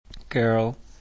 Irisch-Englisch